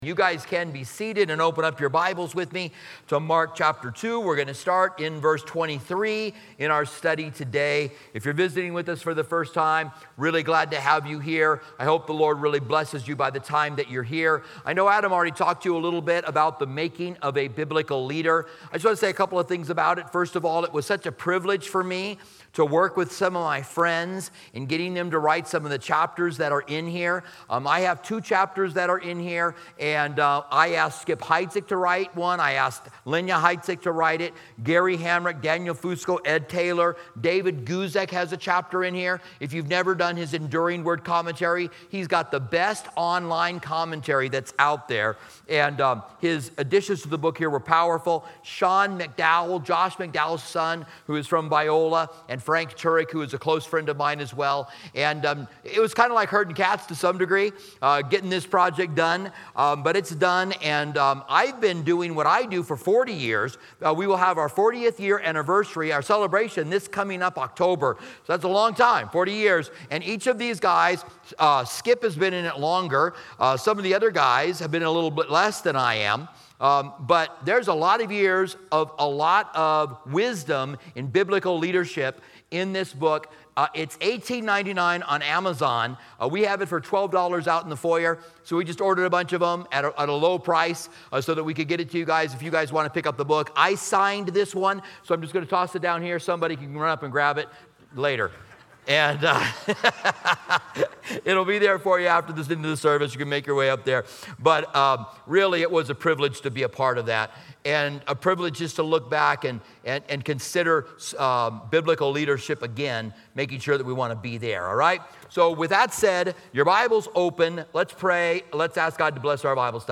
In this Bible study on the Gospel of Mark, we explore Mark 2:23-28, where Jesus challenges the Pharisees' strict interpretation of Sabbath laws. The session highlights that the Sabbath was designed for humanity's benefit, focusing on themes of rest, mercy, and spiritual freedom rather than strict compliance with rules.